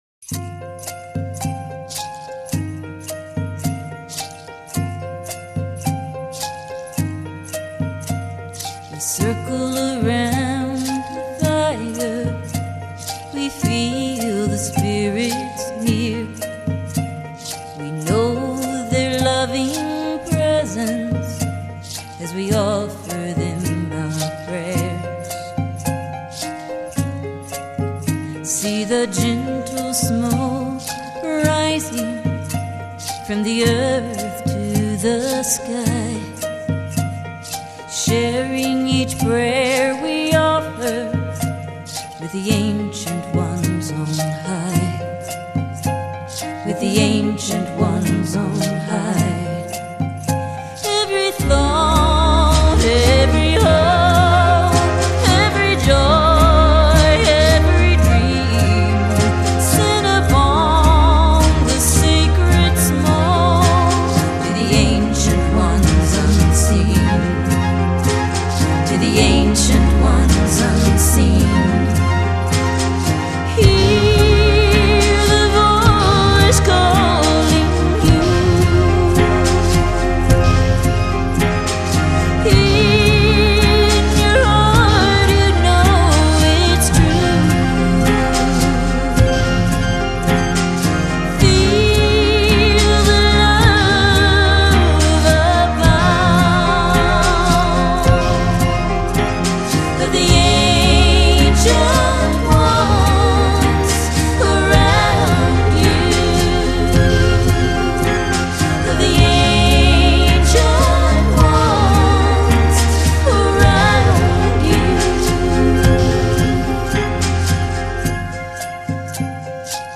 全曲如同置身天堂